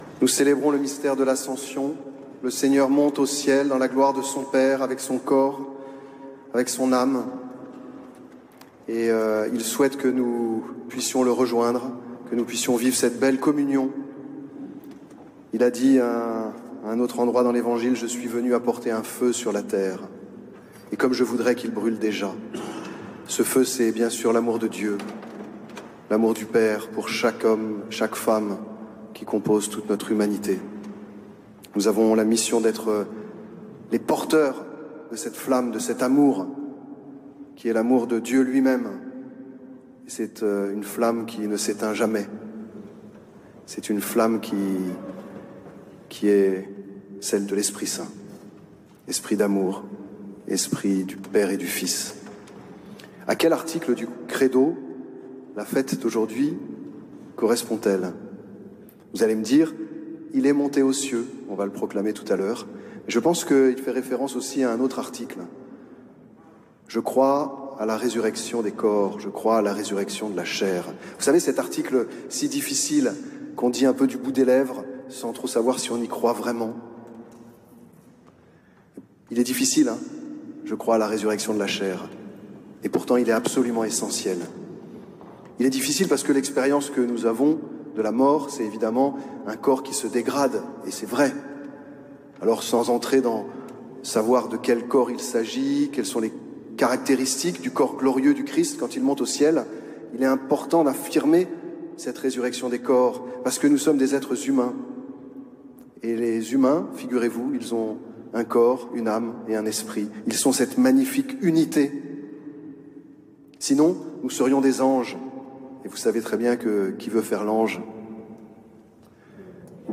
Homélie de Mgr Emmanuel Gobilliard pour l'arrivée de la flamme olympique ( 08/05/24 )
homelie.mp3